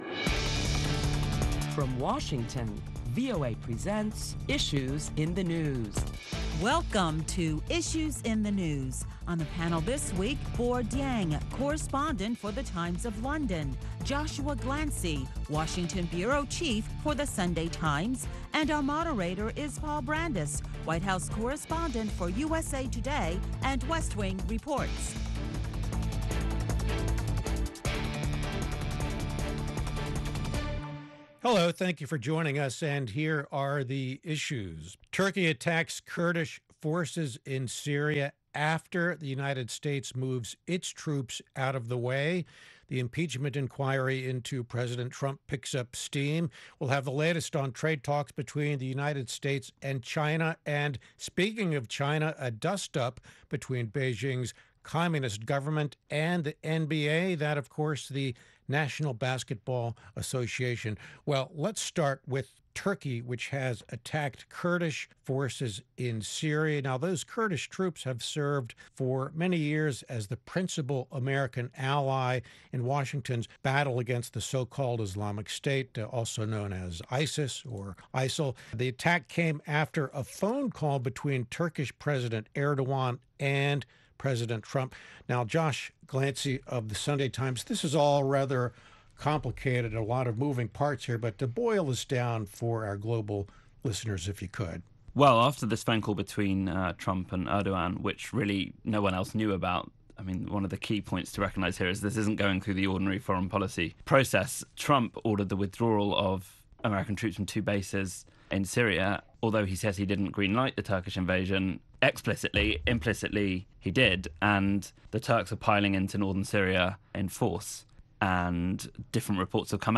Listen to a panel of prominent Washington journalists as they deliberate the latest top stories that include Ukraine’s president says there was no blackmail involved in his phone conversation with President Trump.